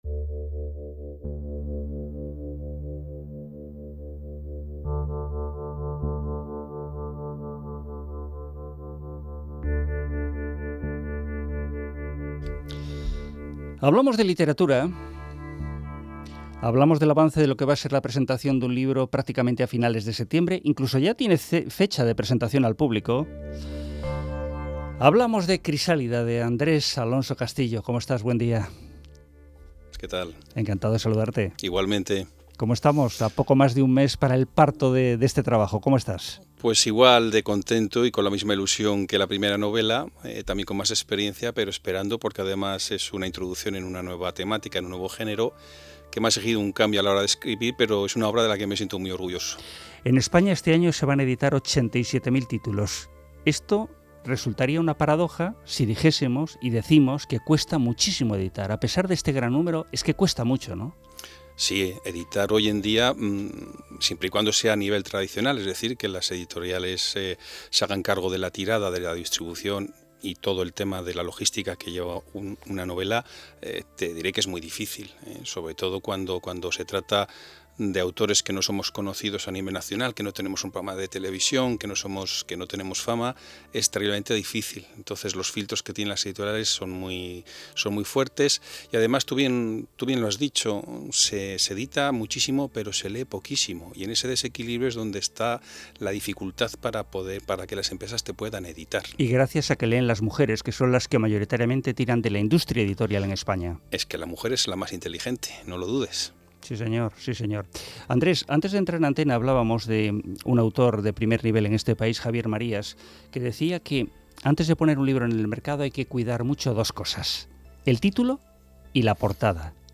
Entrevista realizada en SER Miranda